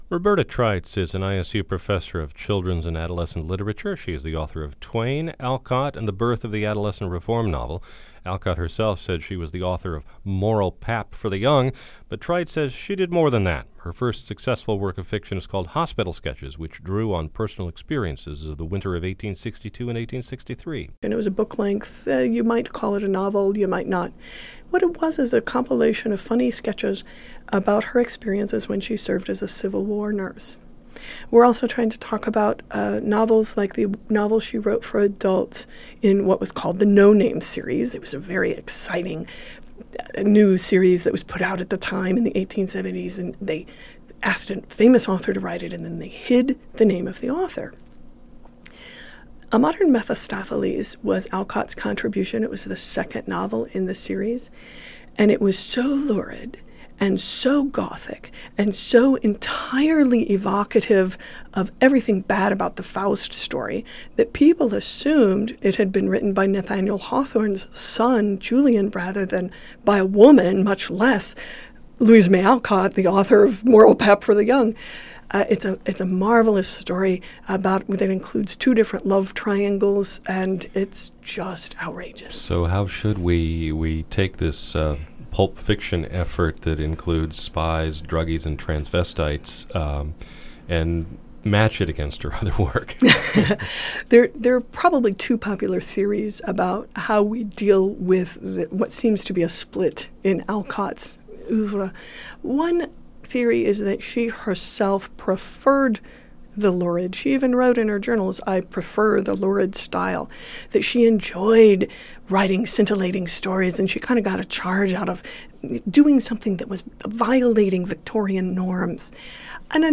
This 4 minute and 37 second interview is well worth the listen with some tantalizing tidbits.